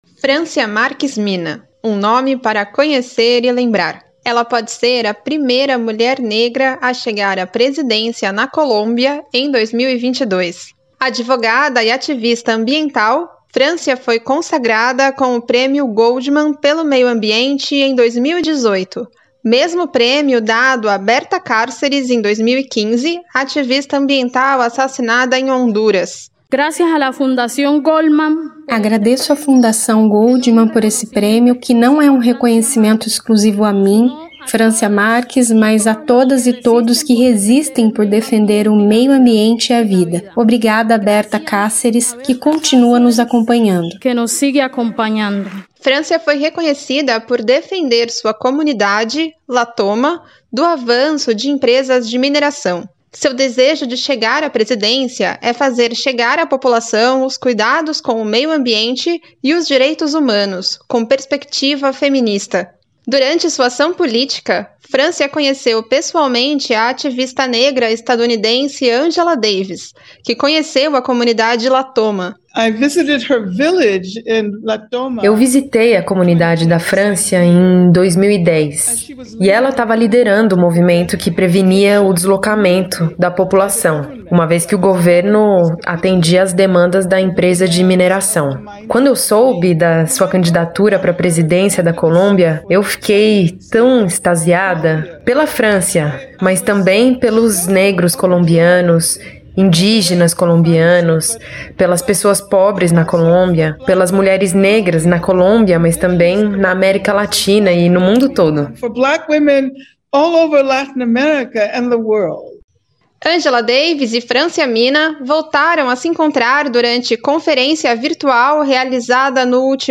Encontro de gigantes: Angela Davis conversa com Francia Márquez, pré-candidata na Colômbia